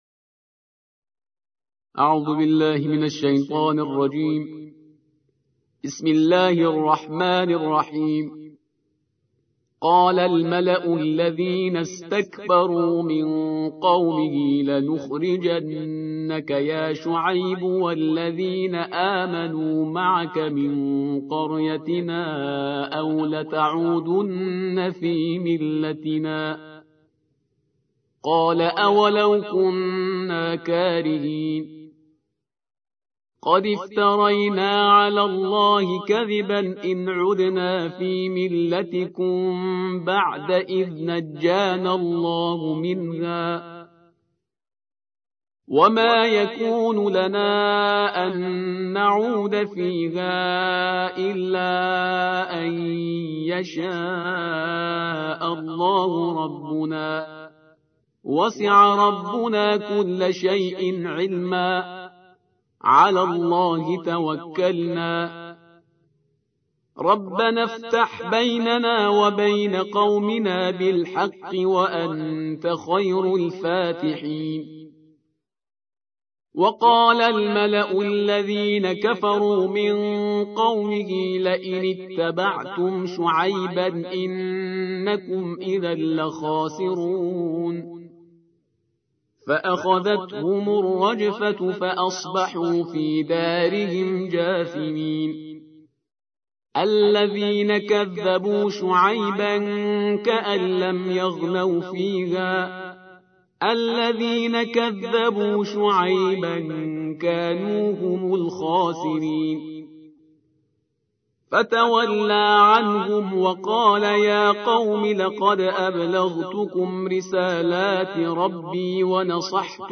الجزء التاسع / القارئ